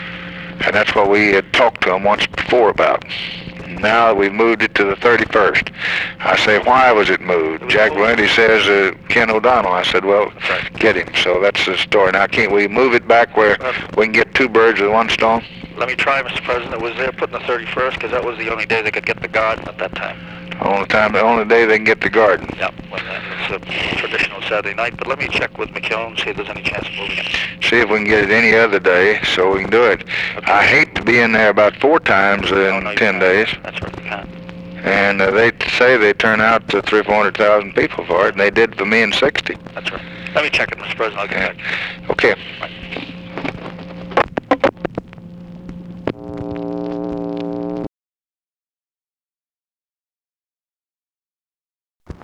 Conversation with KEN O'DONNELL, September 8, 1964
Secret White House Tapes